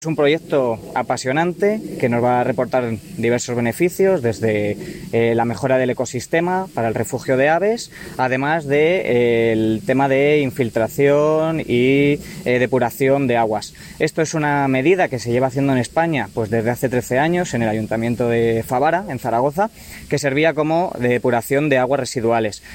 Declaraciones del concejal Rodrigo Vasco